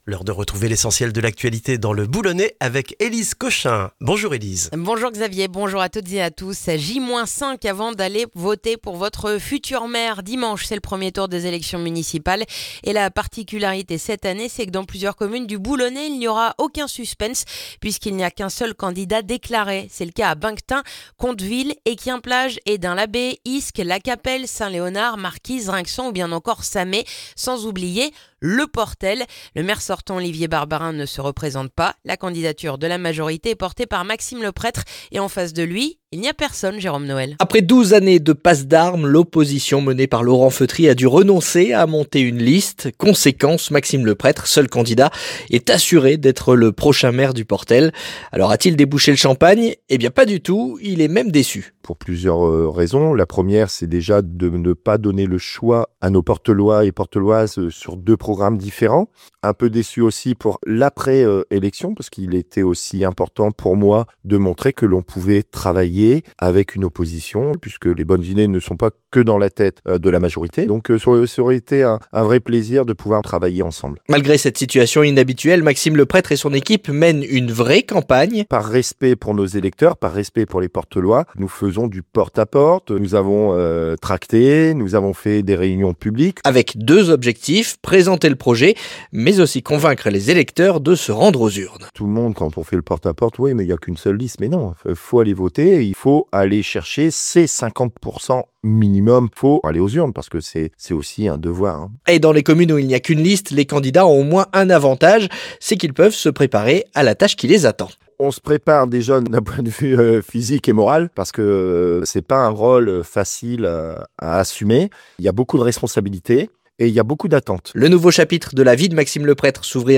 Le journal du mardi 10 mars dans le boulonnais